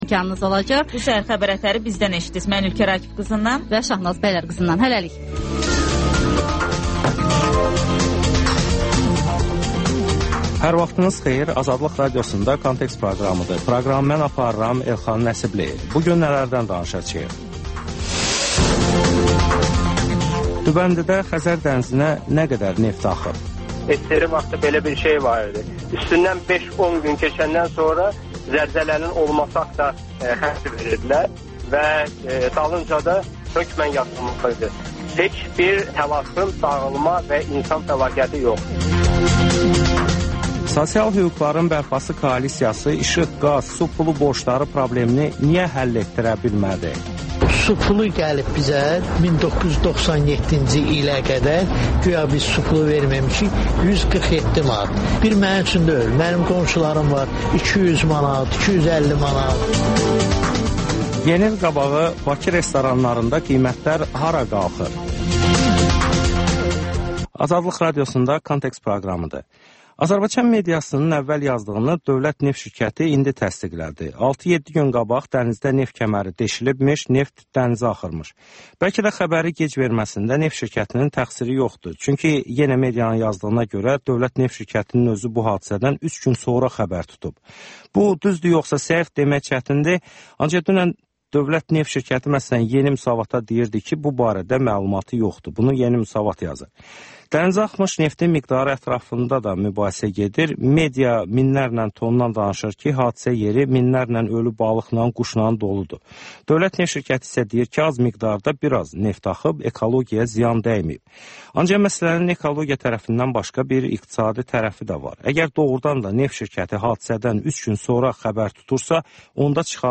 Müsahibələr, hadisələrin müzakirəsi, təhlillər (Təkrar)